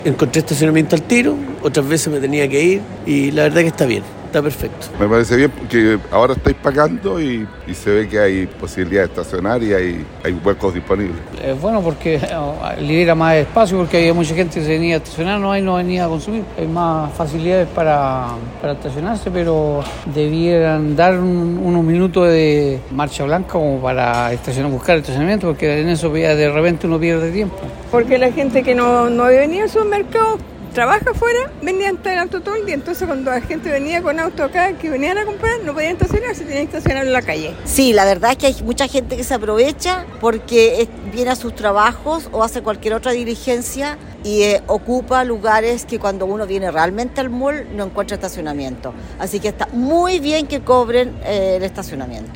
Consultadas por La Radio, diversas personas se mostraron a favor de la nueva norma, que en su primer día ha permitido mejorar la circulación de vehículos asociado al tiempo de compra.